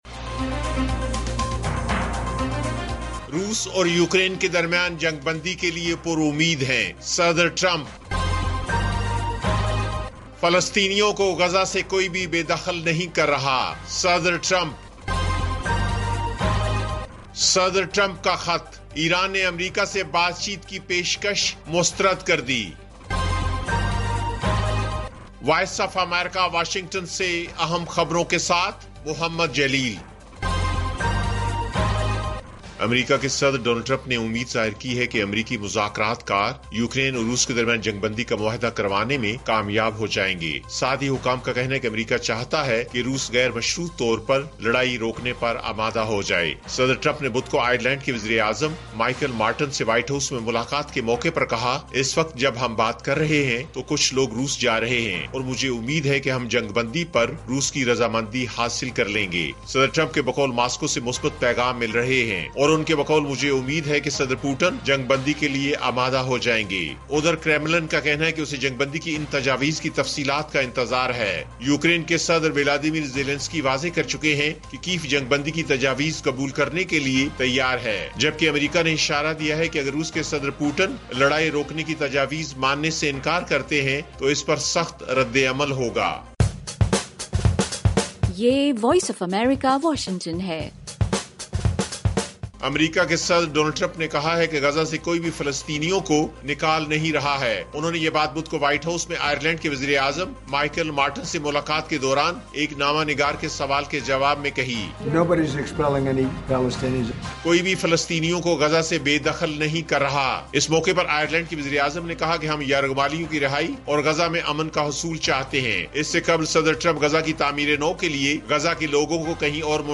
ایف ایم ریڈیو نیوز بلیٹن: شام 5 بجے